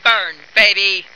flak_m/sounds/female2/int/F2burnbaby.ogg at 86e4571f7d968cc283817f5db8ed1df173ad3393